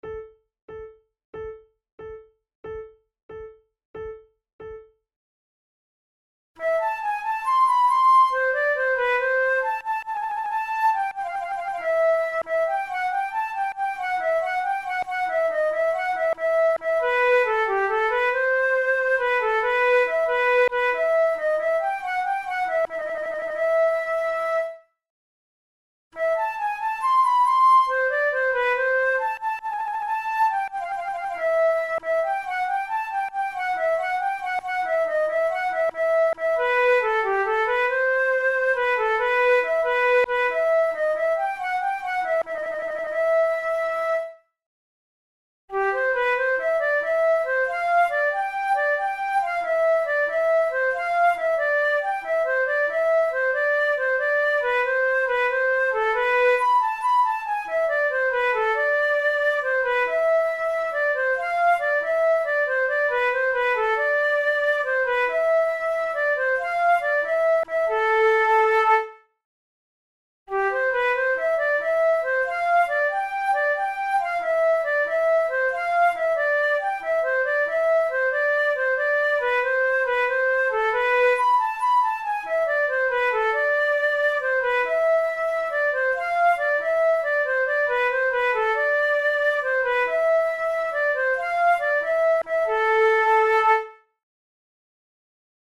Sheet Music MIDI MP3 Accompaniment: MIDI
This is the fourth and final movement of a sonata in A minor for two flutes by the German Baroque composer and music theorist Johann Mattheson.
Categories: Baroque Jigs Sonatas Written for Flute Difficulty: intermediate